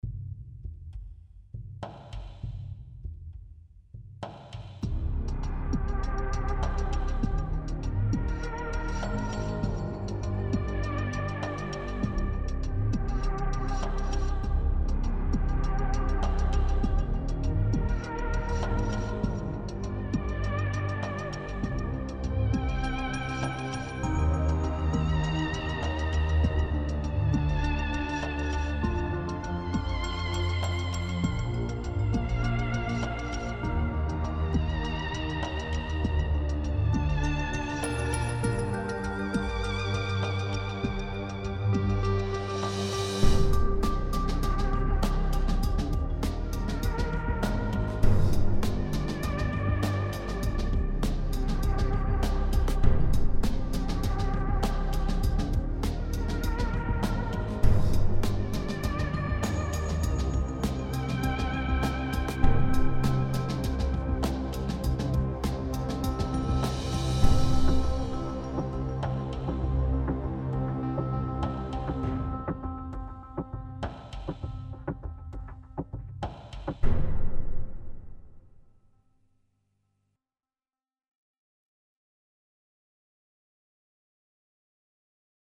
Desolation – film Trailer Music